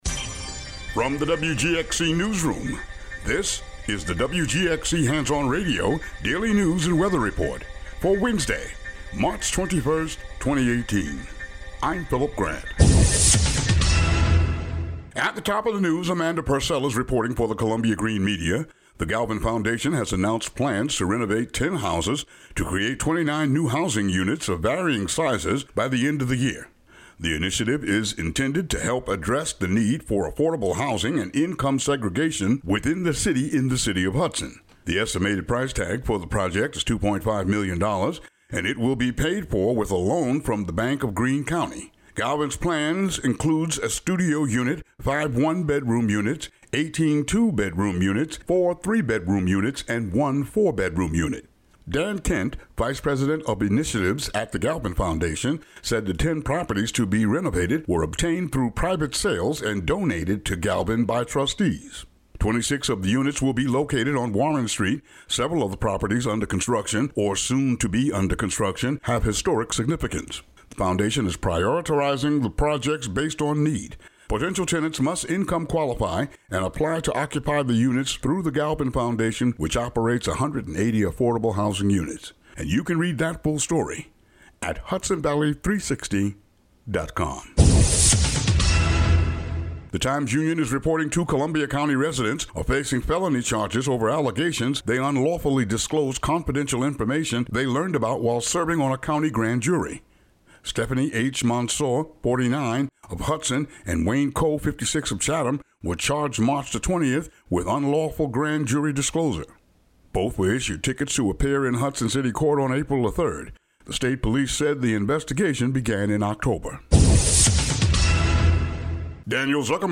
The local news for the WGXC listening area.